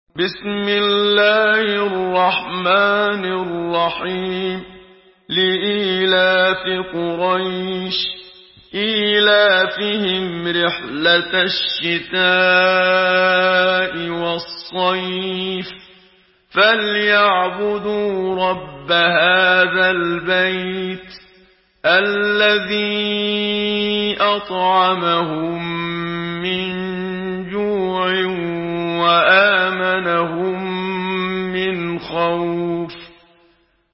سورة قريش MP3 بصوت محمد صديق المنشاوي برواية حفص
مرتل